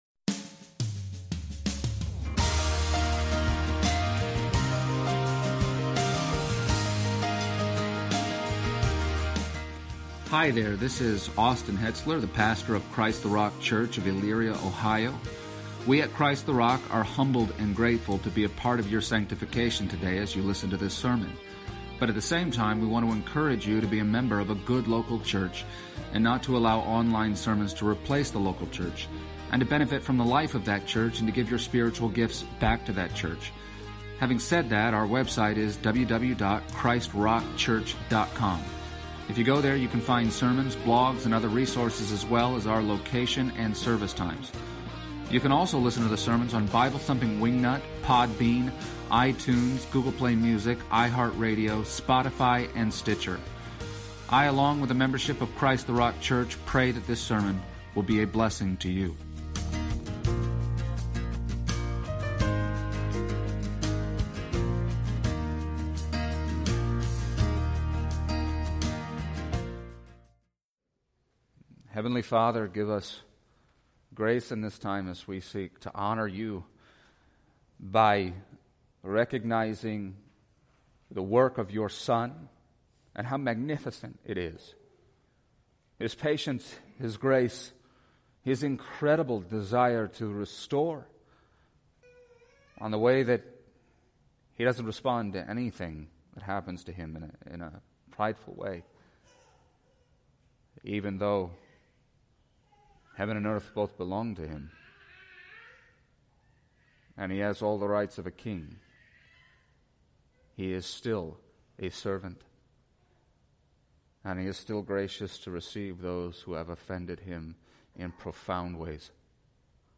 Passage: John 21:1-14 Service Type: Sunday Morning %todo_render% « Beyond All Doubt Peter Reconciled